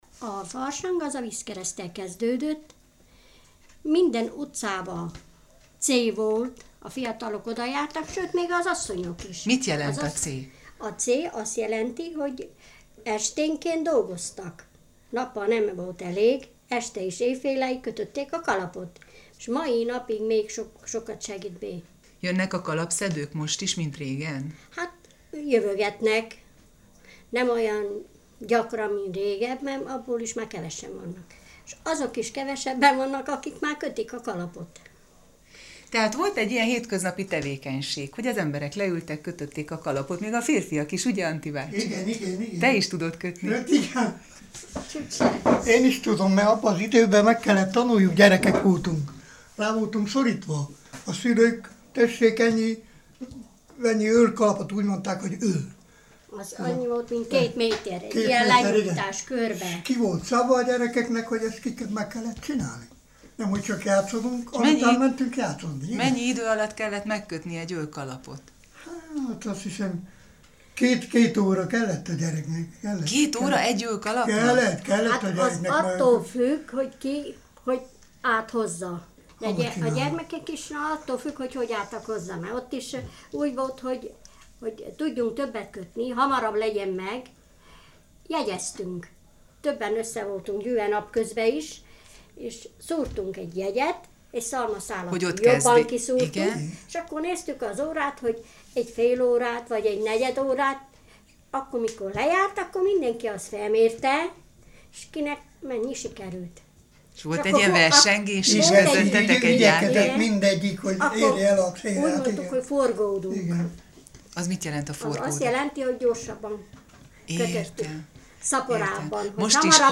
a konyhában, a ropogó tűz mellett zajlik az emlékezés a farsangos időkre.